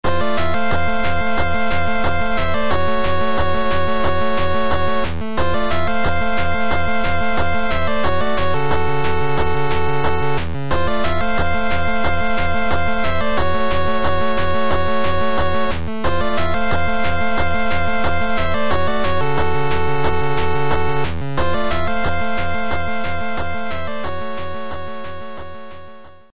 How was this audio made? Game Boy version